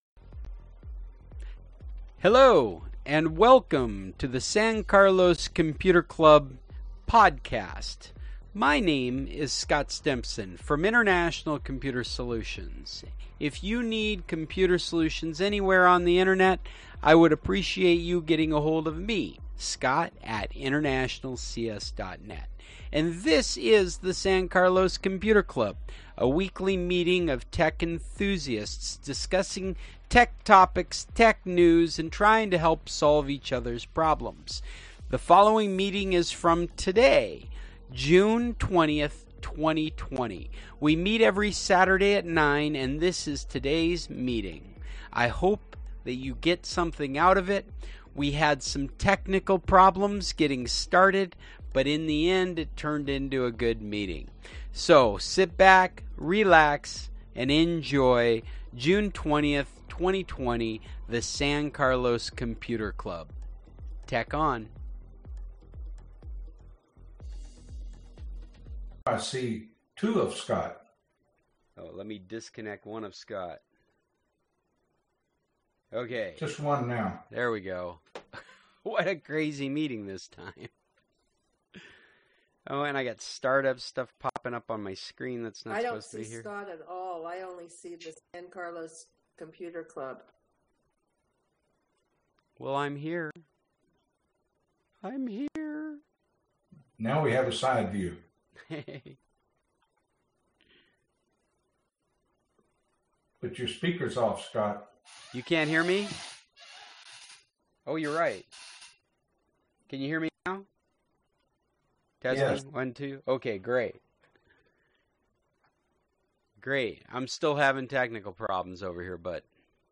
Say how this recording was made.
So glad you’ve come back to join us on another Saturday morning to talk tech and troubleshoot the technology we use for the meeting. It was never my intention to bring people along on that journey, but I am curious what that creates for an audio experience.